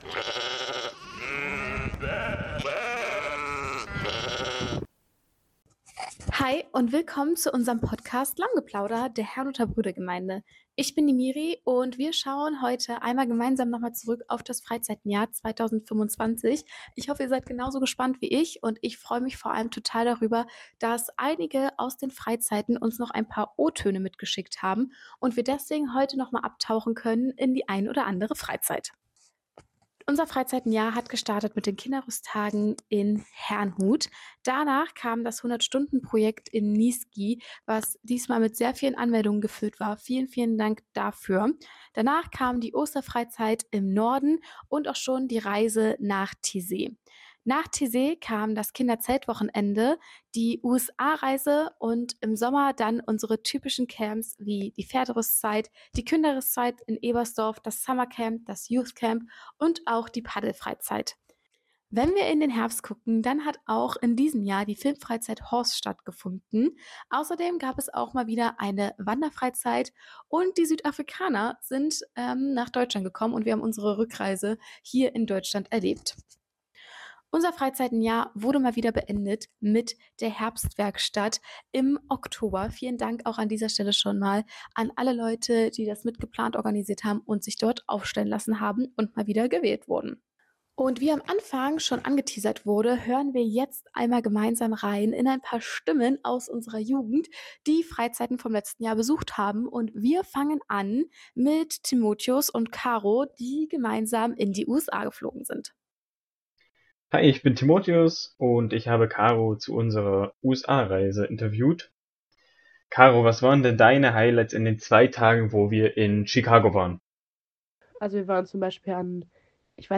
Trotzdem lohnt es sich noch einmal einzutauchen und das Jahr vorüberziehen zu lassen. Wir freuen uns besonders darüber von einigen Teilnehmenden und Teamenden der Freizeiten direkt zu hören.